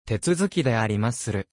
Japanese Unit Voice
Japanese unit responses.
And couldn't resist making some Japanese unit voices with it.